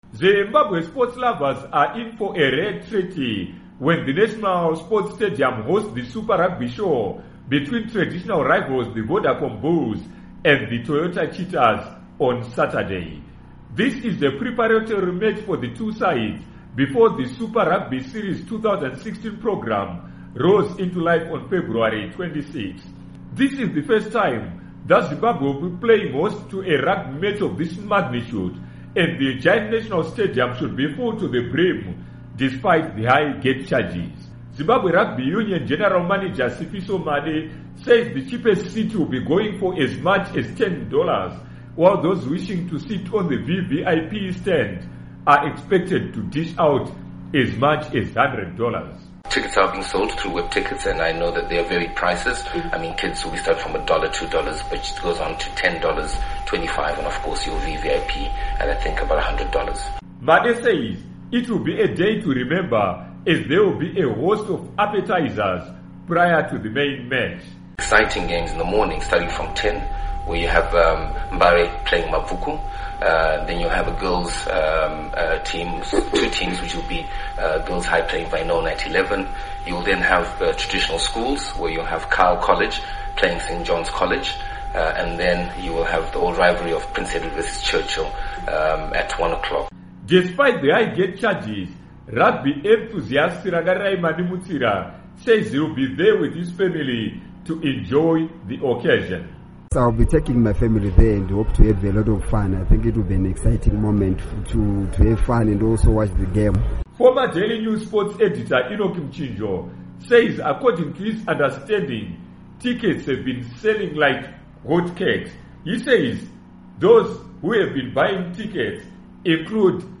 Report on Rugby Derby